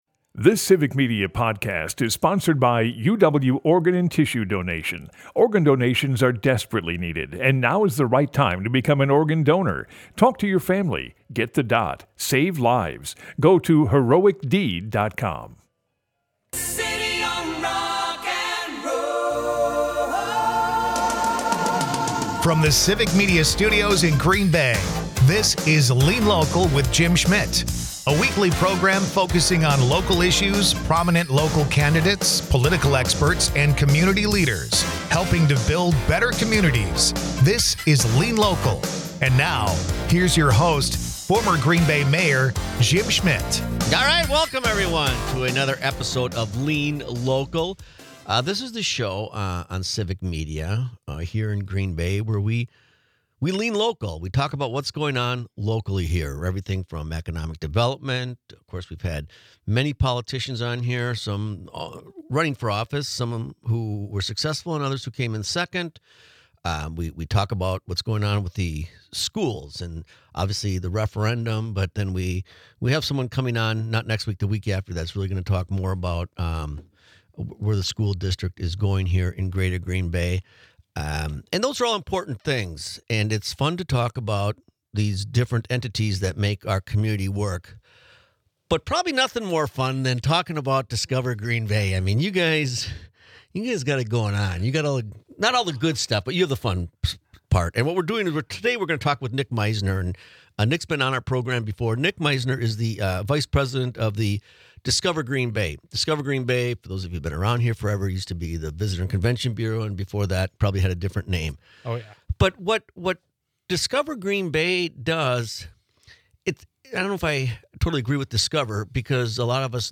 Lean Local is a part of the Civic Media radio network and airs Sunday's from 1-2 PM on WGBW .
Dive into the heart of community issues with 'Lean Local,' hosted by former Green Bay Mayor Jim Schmitt.